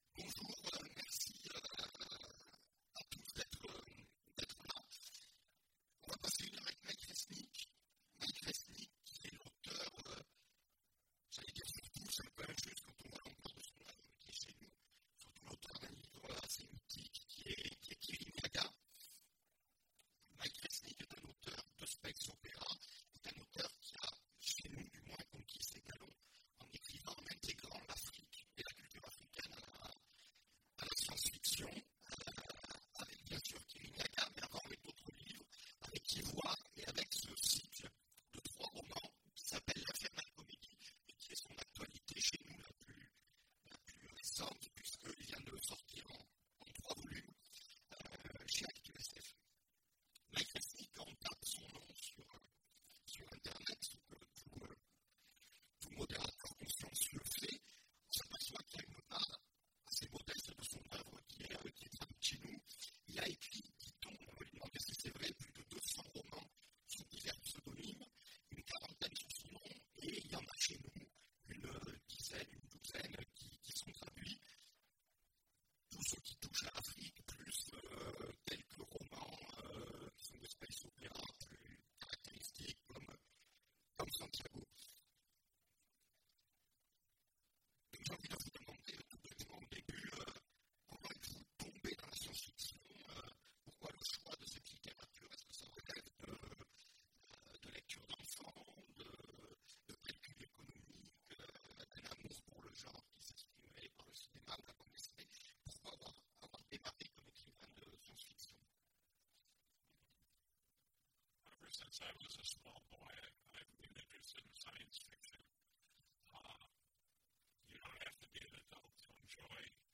Imaginales 2016 : Entretien avec… Mike Resnick
Mike Resnick Télécharger le MP3 à lire aussi Mike Resnick Genres / Mots-clés Rencontre avec un auteur Conférence Partager cet article